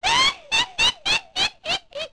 different to distinguish them in Ocarina of Time.
OOT_Twinrova_Koume_Laugh.wav